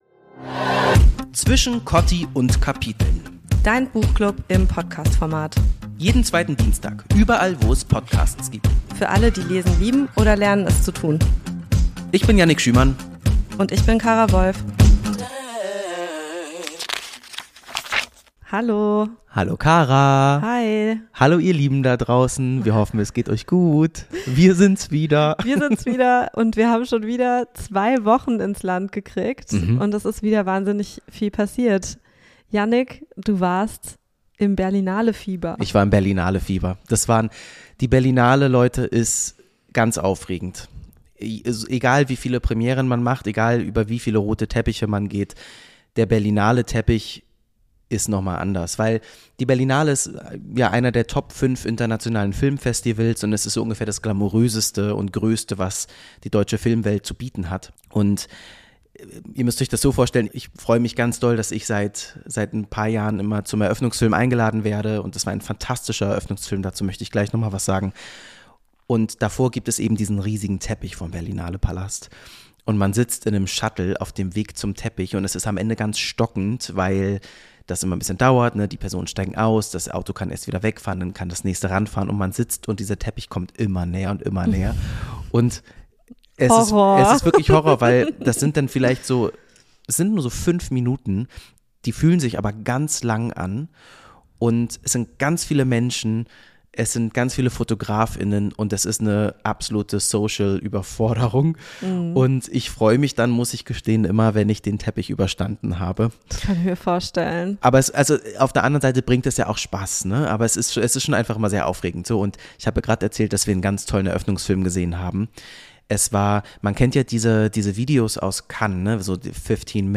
Freut euch auf ein Gespräch über Rollenklischees, Beta-Momente und die Erkenntnis, das wahre Stärke am Ende einfach die Fähigkeit ist, Schwäche zuzugeben.